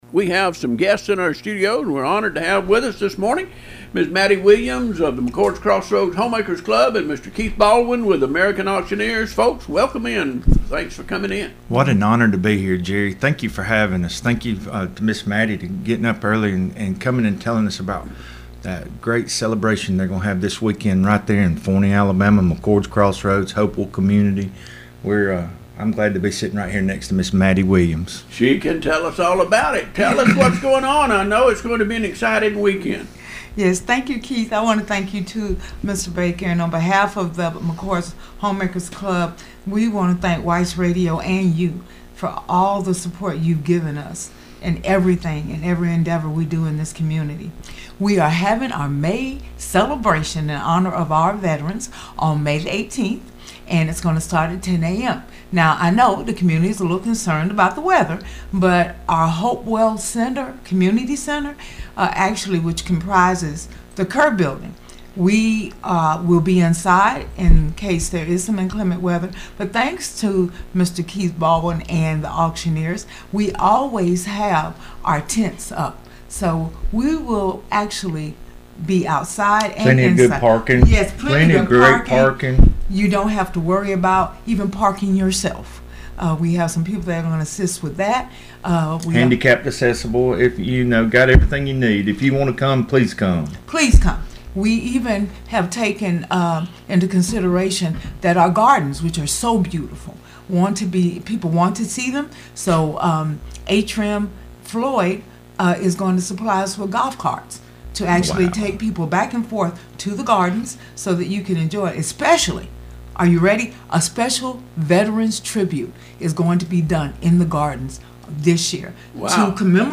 SAT-INTERVIEW-1.mp3